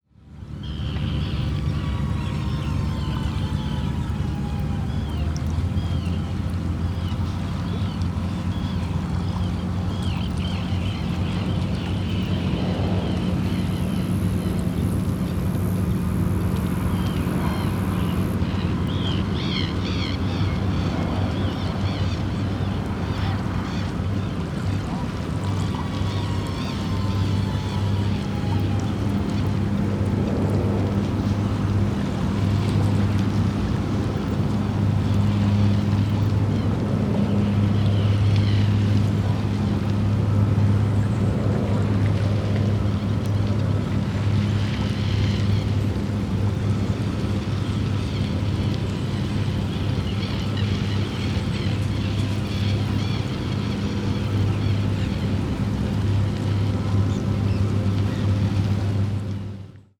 The album is a collection of edited field recordings dealing with the concept of affective atmosphere and human/listener positioning within the sonic environment.
The recordings were taken in the Venice lagoon in 2017, and explored the sonic environment of some abandoned islands of the lagoon, namely Carbonera, Buel del Lovo, and Poveglia. Commonly described as places haunted by spirits, these islands unfolded a deeply natural environment while showing at the same time their closeness to human made infrastructures, such as the Venice international Airport.